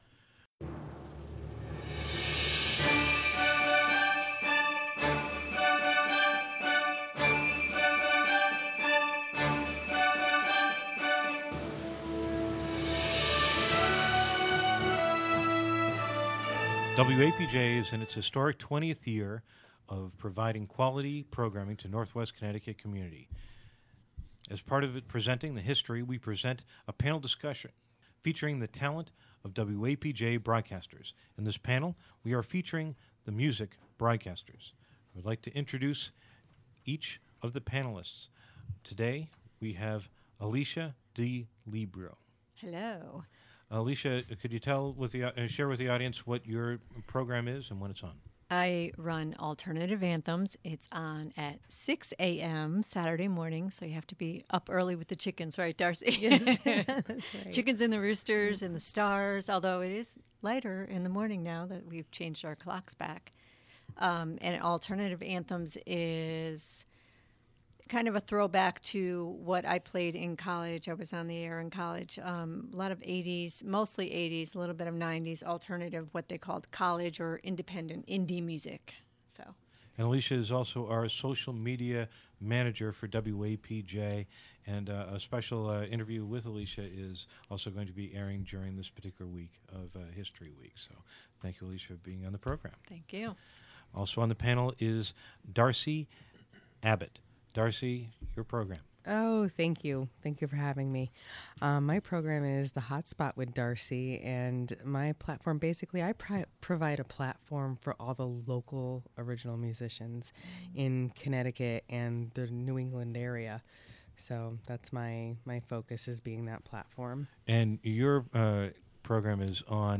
This the second (of two) MUSIC panel discussions recorded in November 2017.